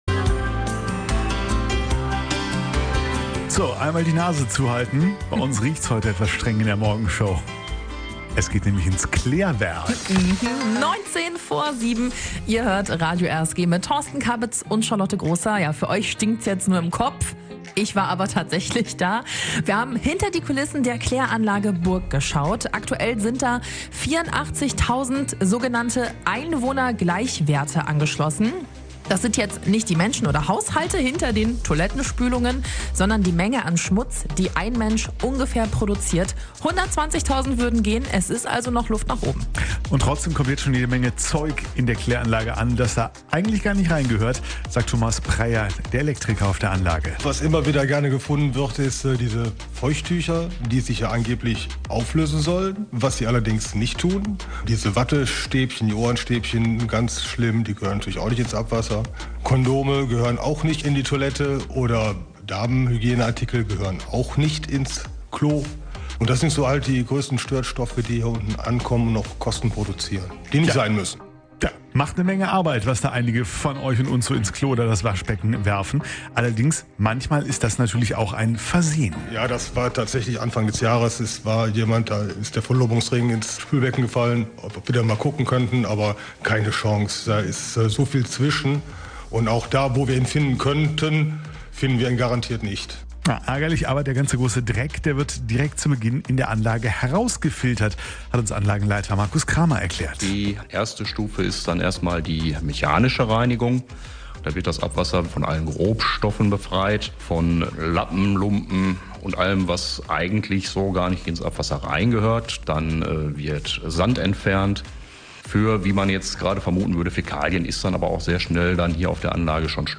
Reportage aus dem Klärwerk Solingen Burg - Teil 1